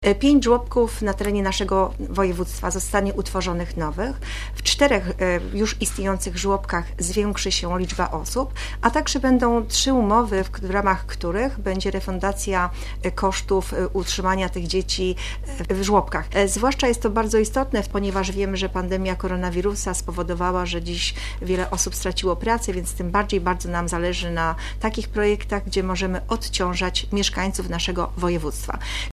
– Wśród 18 zawartych umów, aż 12 dotyczy rozwijania opieki żłobkowej, na którą Zarząd Województwa przeznaczył ponad 11,5 mln zł – powiedziała podczas transmitowanej on-line uroczystości podpisania umów wicemarszałek Renata Janik.